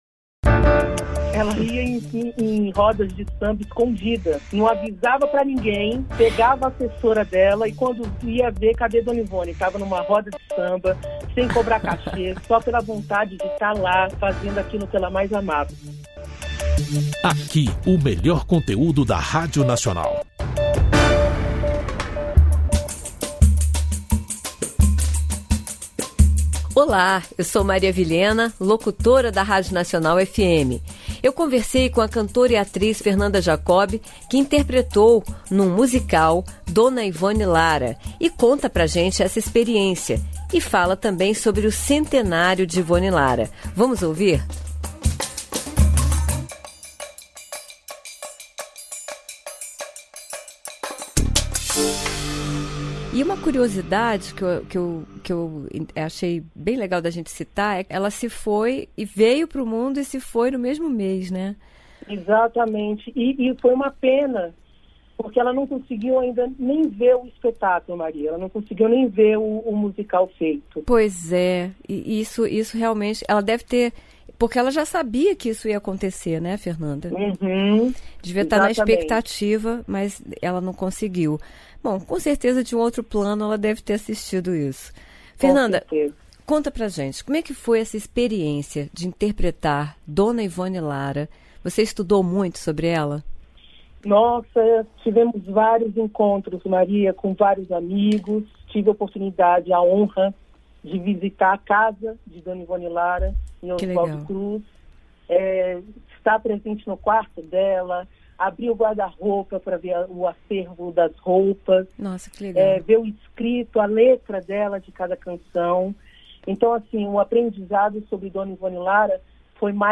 Podcast Entrevista é Nacional: os 100 anos da dona do samba